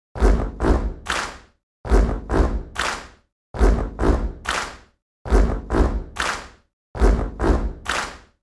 Звуки хлопков
6. Хлопают под ритм